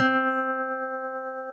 Rusty Guitar2.wav